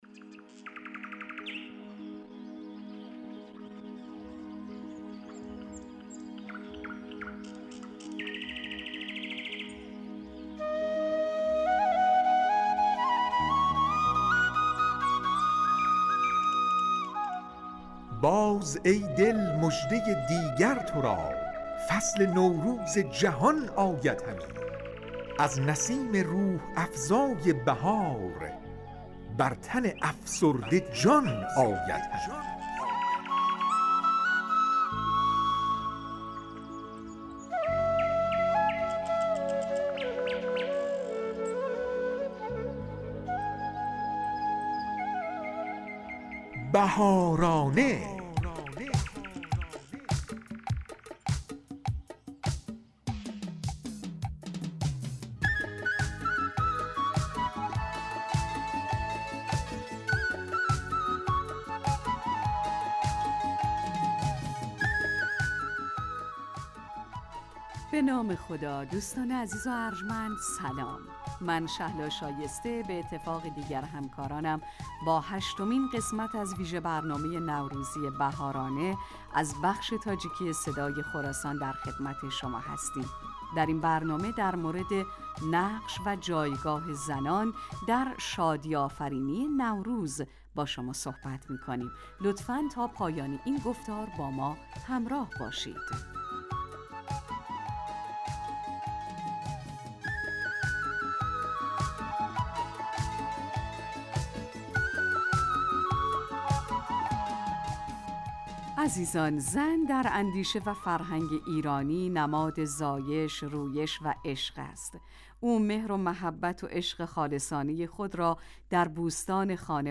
"بهارانه" ویژه برنامه نوروزی رادیو تاجیکی صدای خراسان است که به مناسبت ایام نوروز در این رادیو به مدت 30 دقیقه تهیه و پخش می شود.